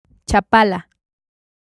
Lake Chapala (Spanish: Lago de Chapala, [tʃaˈpala]